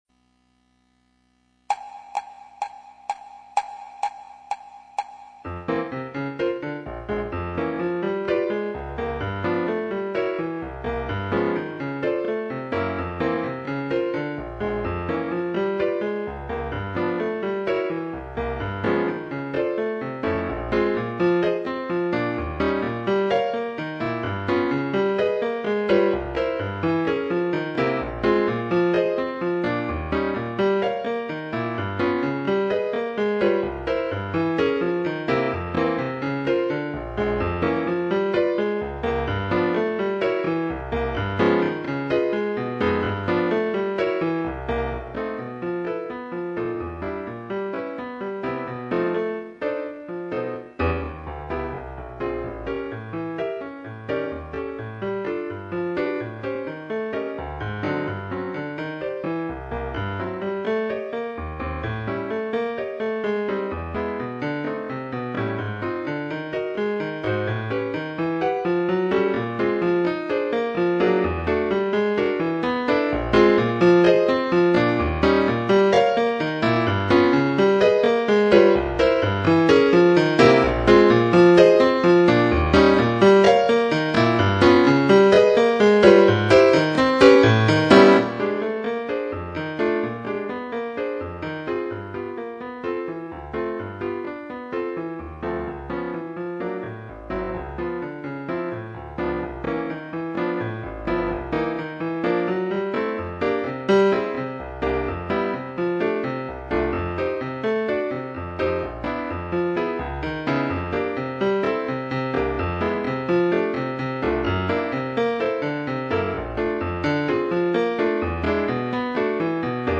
Mambo piano Slower